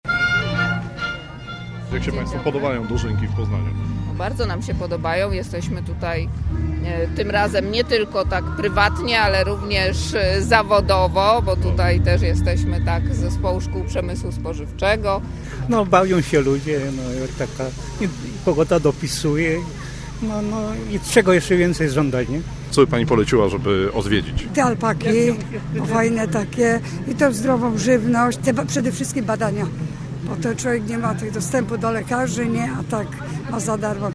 Na miejscu z uczestnikami poznańskich dożynek rozmawiał nasz reporter.